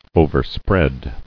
[o·ver·spread]